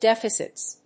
/ˈdɛfʌsʌts(米国英語), ˈdefʌsʌts(英国英語)/